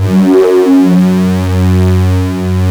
OSCAR 10 F#2.wav